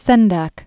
(sĕndăk), Maurice 1928–2012.